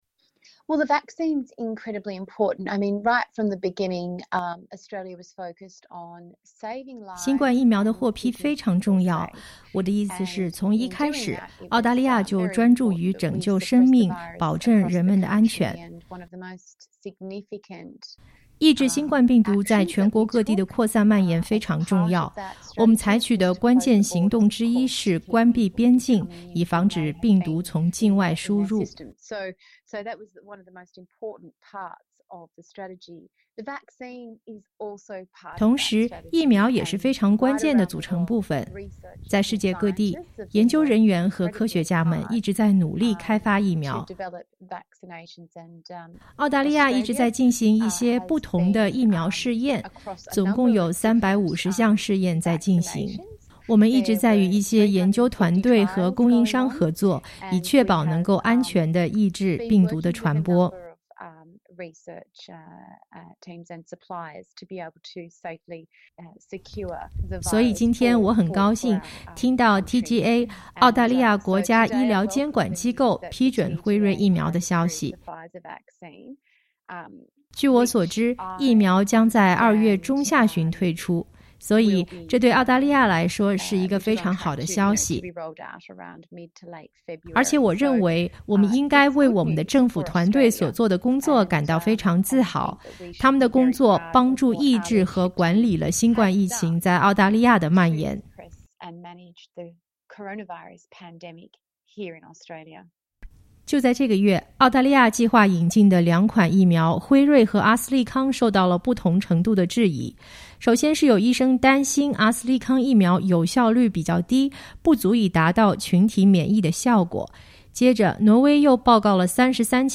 辉瑞疫苗在澳洲获批，首批疫苗接种工作将在二月底至三月初正式开启。悉尼Reid选区联邦议员马丁女士（Fiona Martin）今天在接受SBS普通话采访时表达了对这个消息的欢迎。